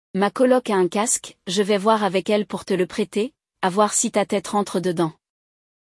Hoje, vamos ouvir uma conversa entre dois amigos que planejam fazer uma viagem.